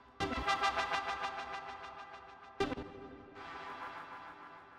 Em (E Minor - 9A) Free sound effects and audio clips
• Sequenced Texture Pad Hit.wav
Sequenced_Texture_Pad_Hit__qRg.wav